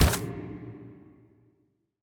Fantasy Click (3).wav